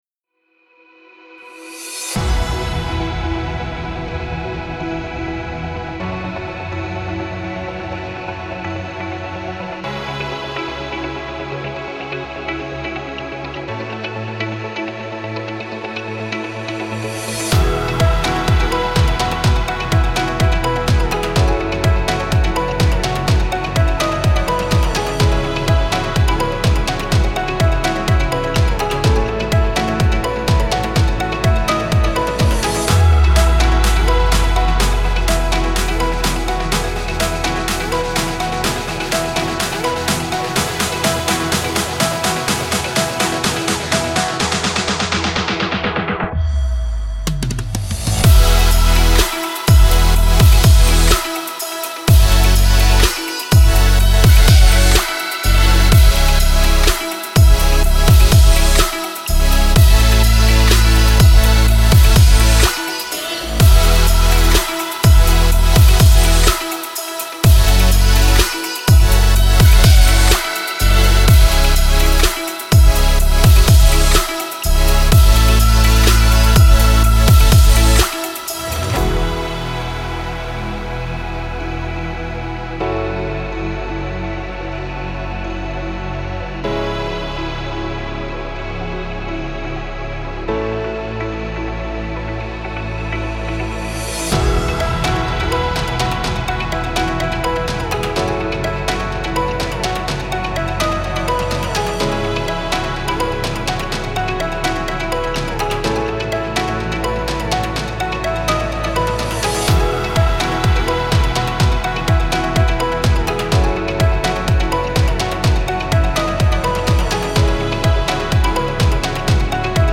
امید‌بخش , پاپ